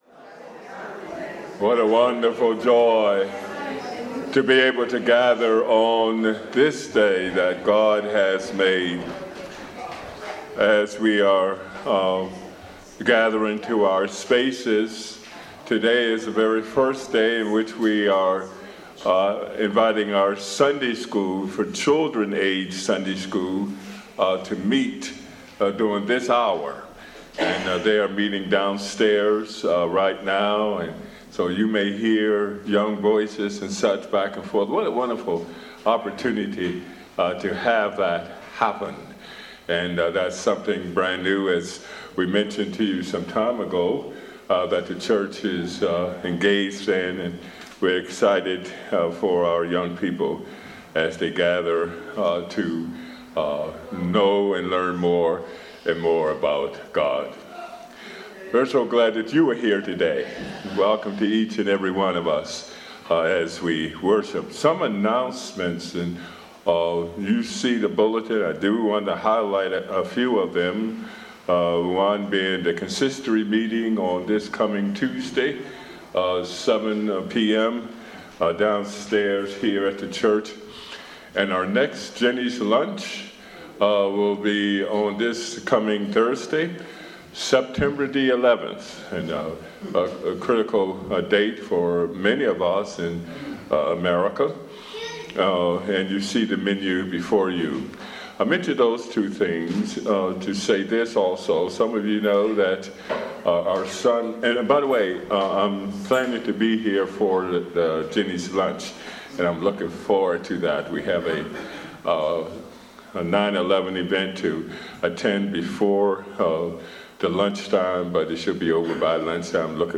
Woestina Reformed Church - Online Service